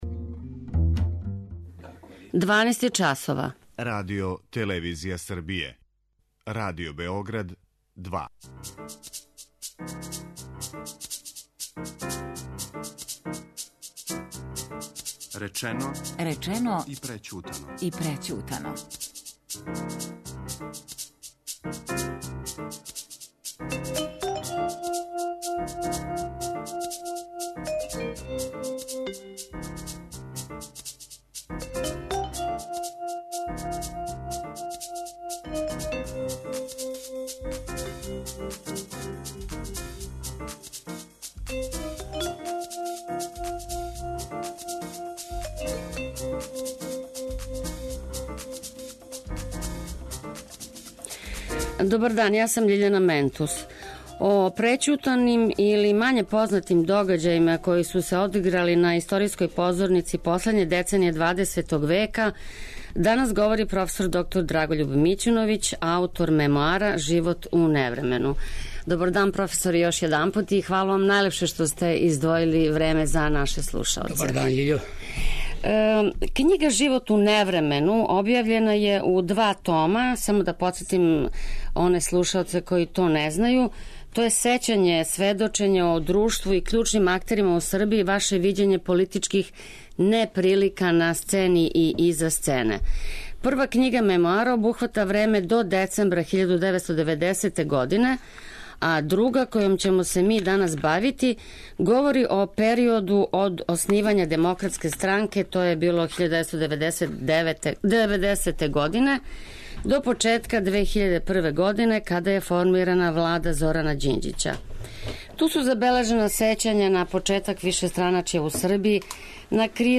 Говори Драгољуб Мићуновић